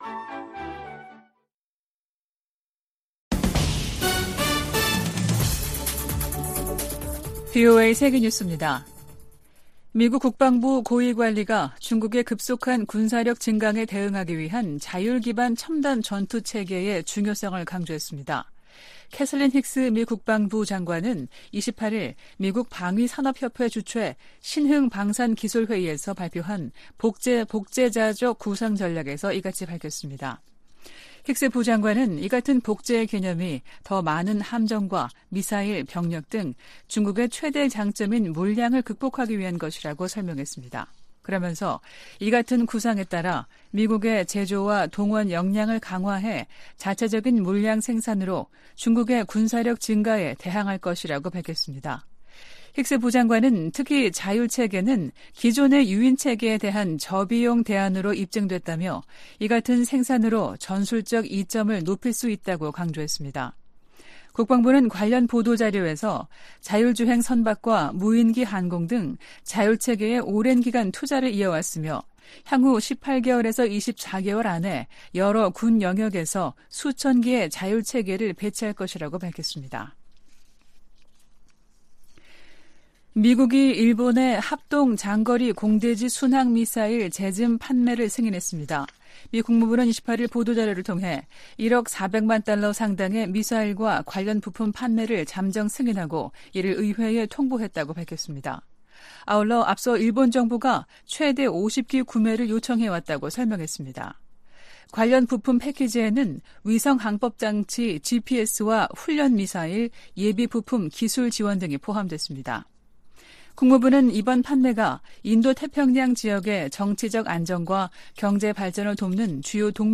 VOA 한국어 아침 뉴스 프로그램 '워싱턴 뉴스 광장' 2023년 8월 30일 방송입니다. 유엔 안보리의 북한 정찰위성 발사 시도 대응 공개회의에서 미한일 등은 반복적으로 이뤄지는 도발을 규탄했습니다. 미 국무부가 후쿠시마 원전 오염처리수 방류 결정을 지지한다는 입장을 밝혔습니다. 북한이 신종 코로나바이러스 감염증 사태 이후 3년 7개월여만에 국경을 개방했습니다.